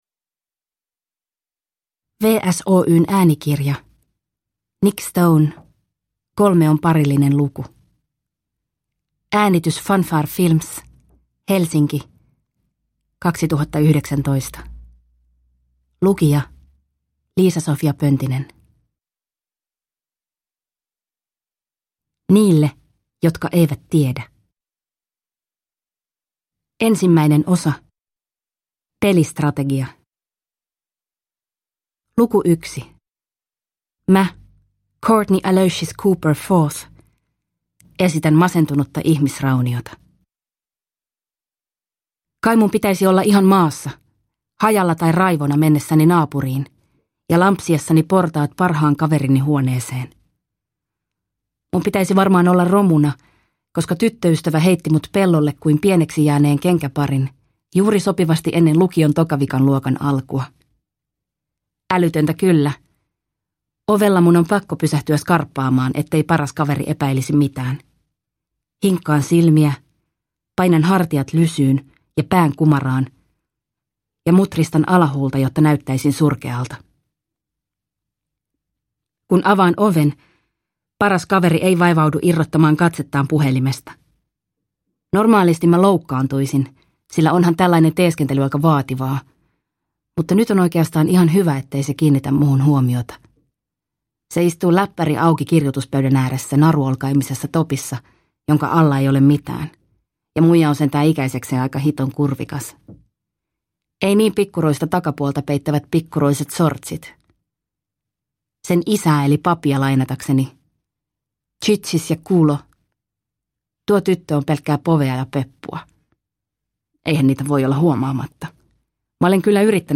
Kolme on parillinen luku – Ljudbok – Laddas ner